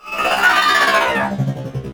combat / enemy / droid / die1.ogg
die1.ogg